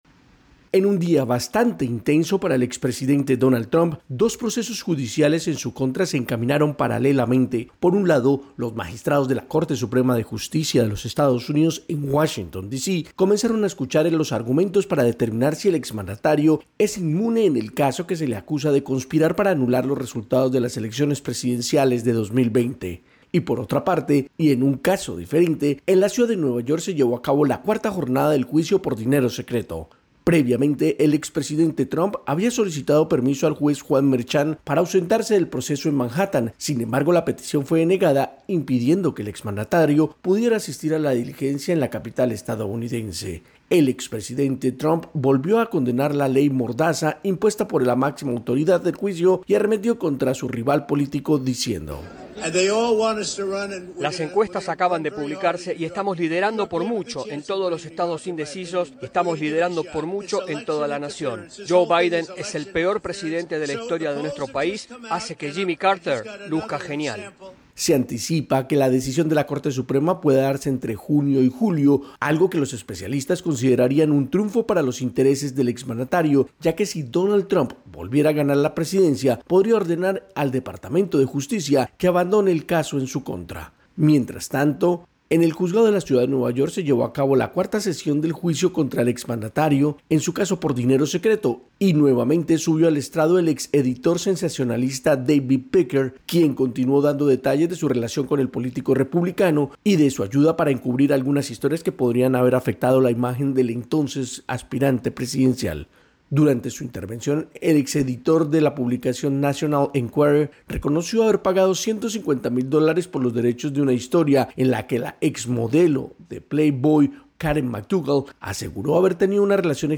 AudioNoticias
desde la Voz de América en Washington, DC.